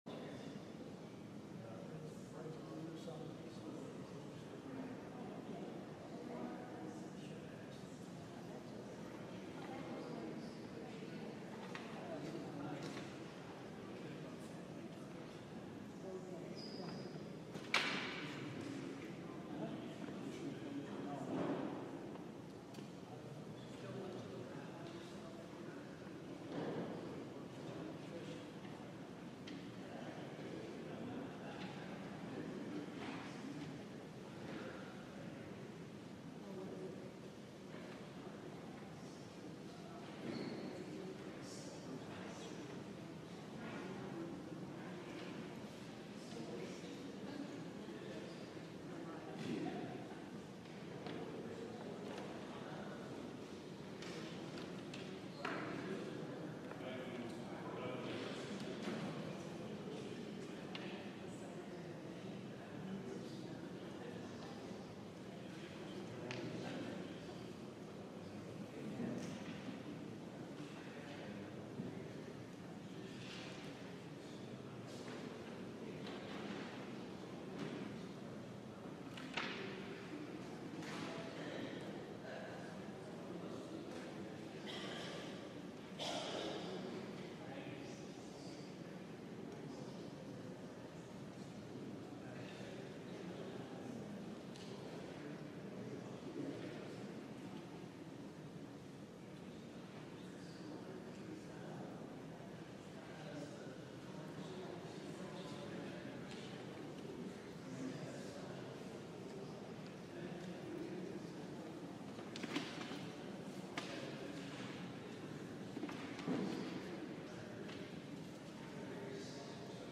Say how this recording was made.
LIVE Evening Worship Service - Answer the Fool. or Don't